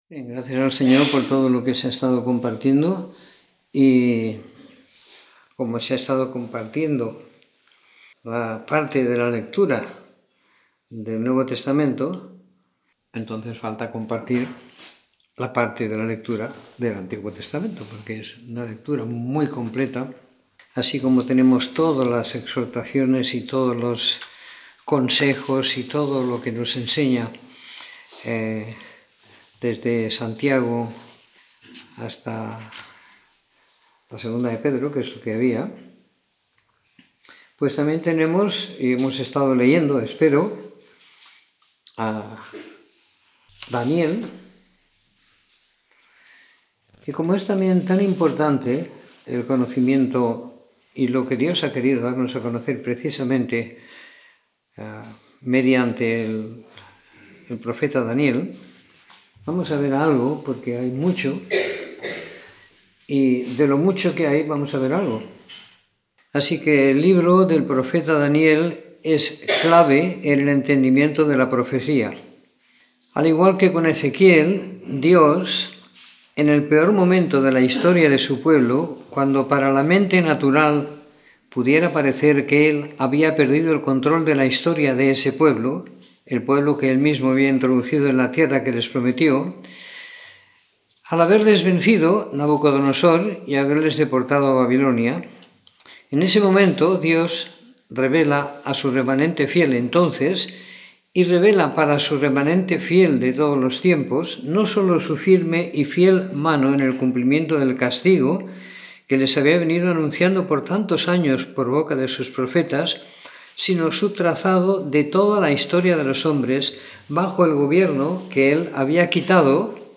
Comentario en Daniel - 11 de Octubre de 2019